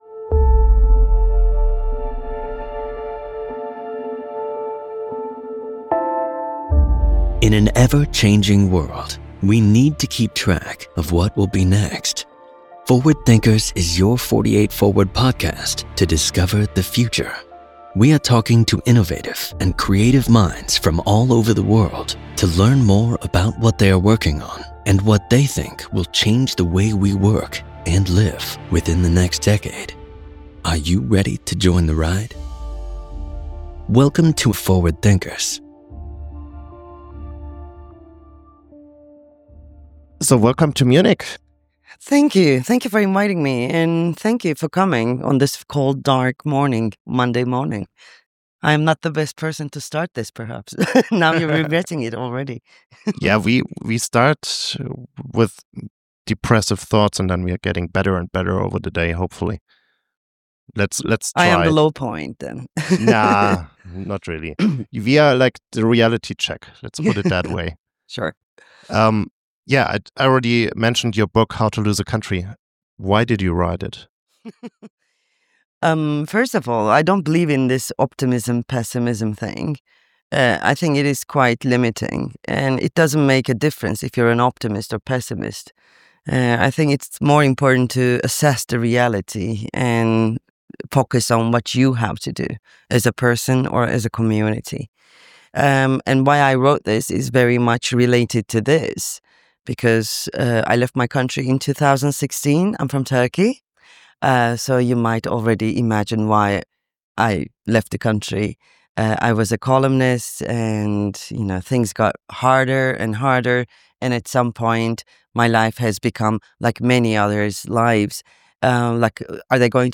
Im Gespräch wird deutlich: Selbstvertrauen, Klarheit und täglicher Widerspruch sind heute die vielleicht wichtigsten politischen Handlungen.